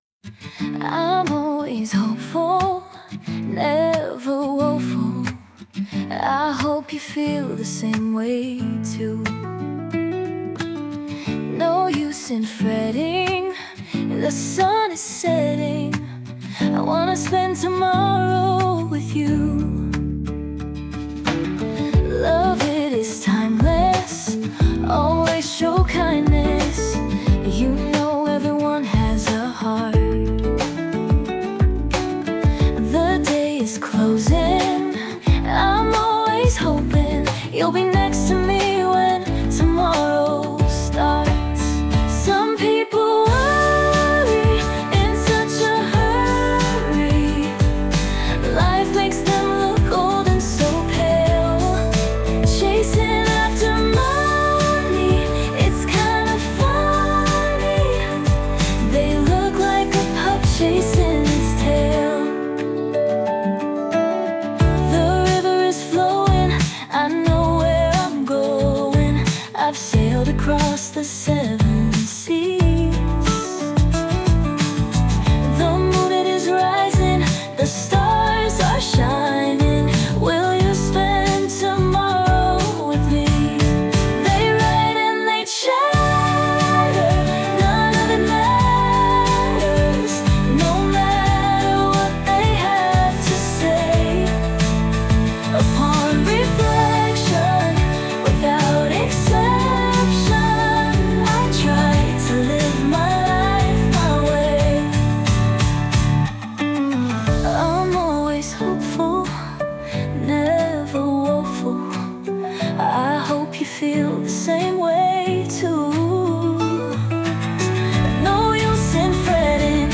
female led Country
heartfelt country song